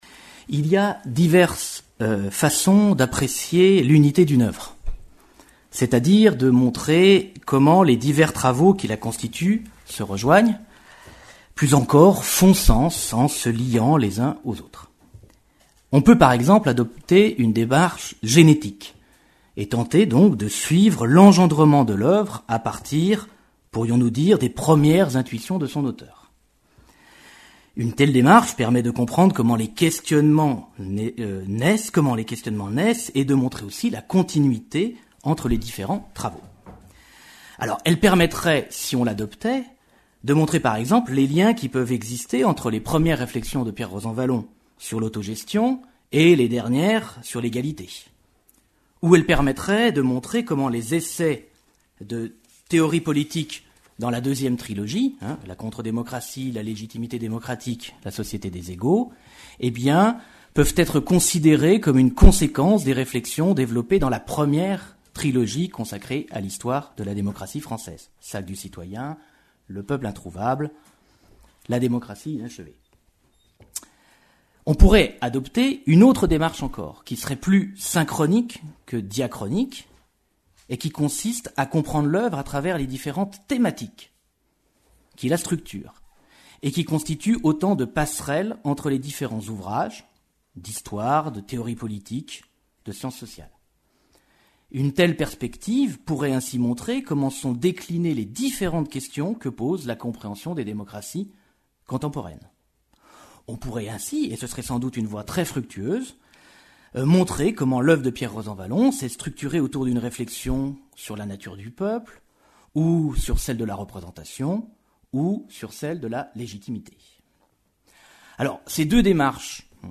L’œuvre de Pierre Rosanvallon est tout entière traversée par une exigence qui la constitue: comprendre la cité contemporaine, produire le savoir qui permette l’intelligence de nos sociétés. Rappeler ce fil conducteur, en introduction de ce colloque, c’est évoquer ce qui est requis pour une telle tâche: faire l’histoire conceptuelle du politique.